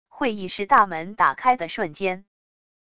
会议室大门打开的瞬间wav音频